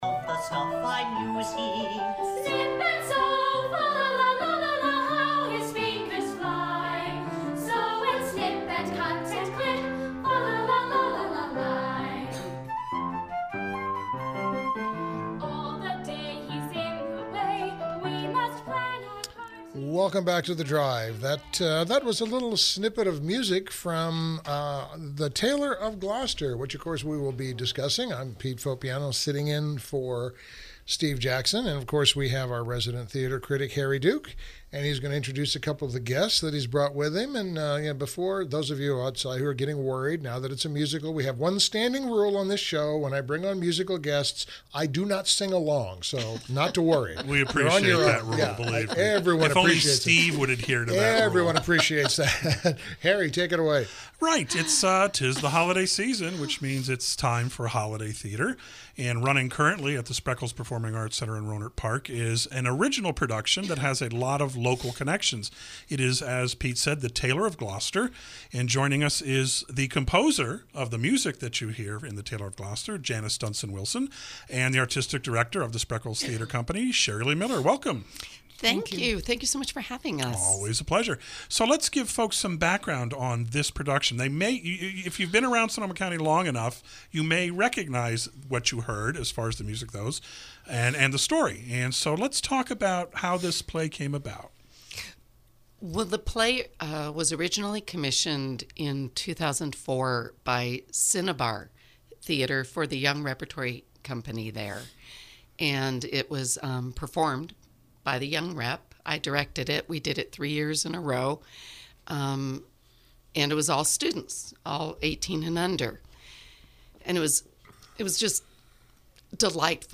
KSRO Interview: “The Tailor of Gloucester”